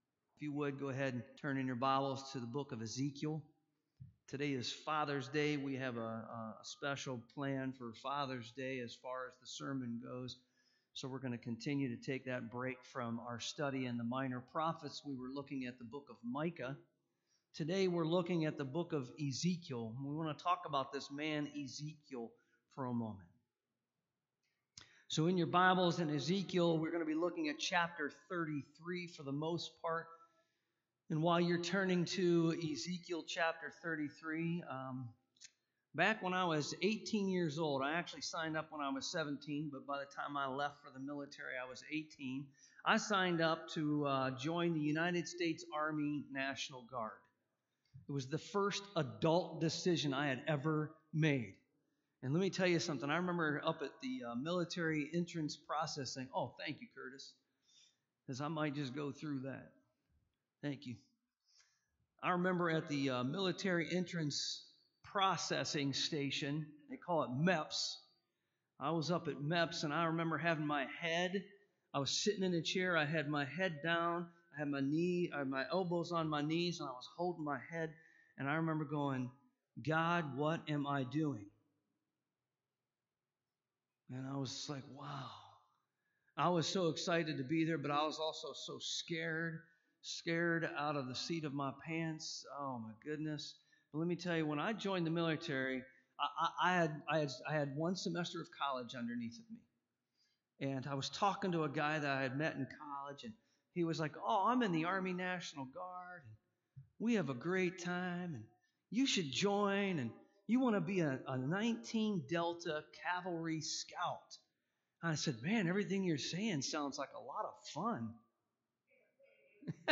3:4-11 Service Type: AM Ezekiel was called upon by God to warn his people of impending judgment.